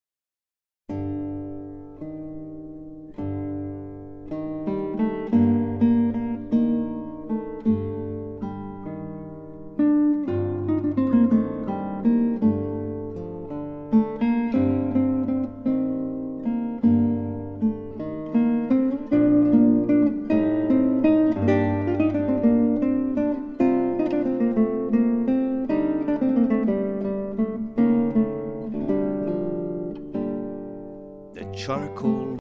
Guitar
Narrator